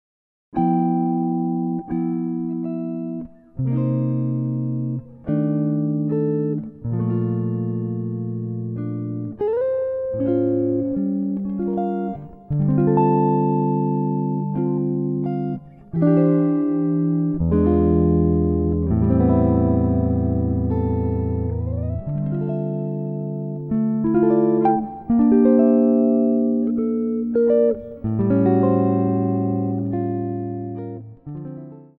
solo guitar arrangements